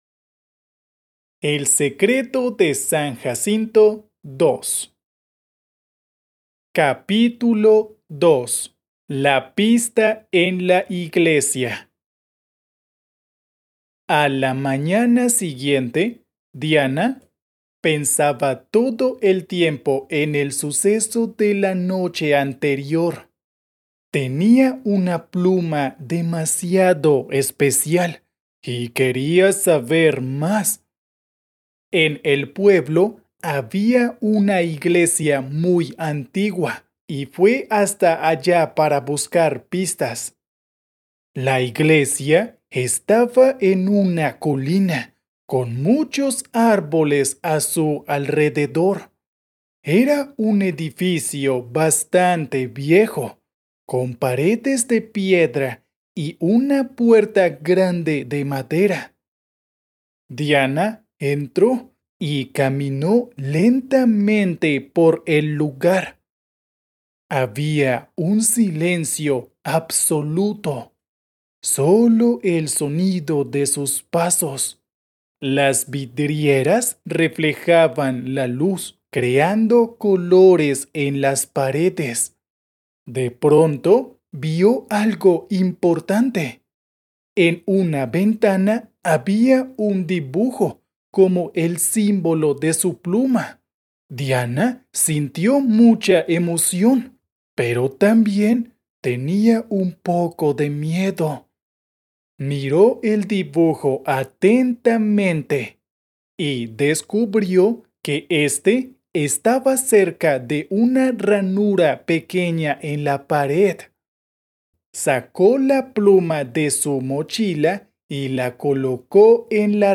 Spanish online reading and listening practice – level A2